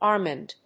/ˈɑrmɑnd(米国英語), ˈɑ:rmɑ:nd(英国英語)/